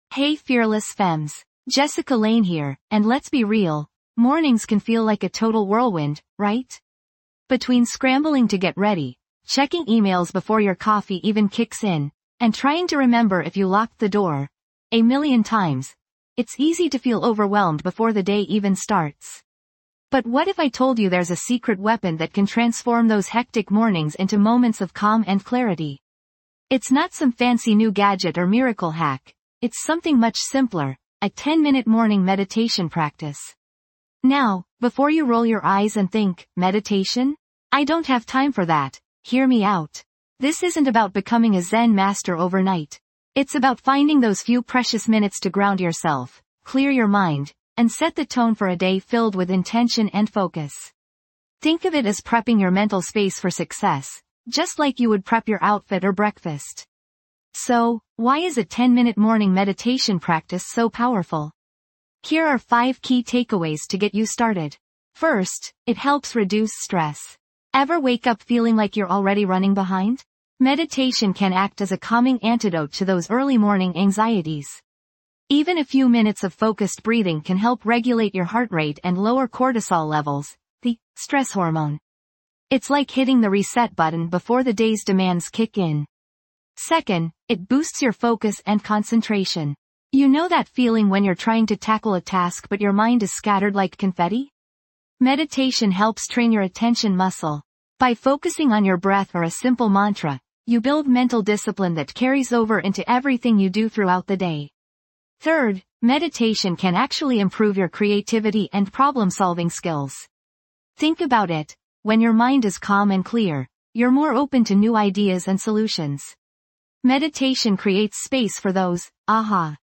Kickstart your day with a 10-minute morning meditation practice on Fearless Femme 5-Minute Daily Motivation. Learn techniques to boost focus, reduce stress, and increase mindfulness for a productive day ahead.
This podcast is created with the help of advanced AI to deliver thoughtful affirmations and positive messages just for you.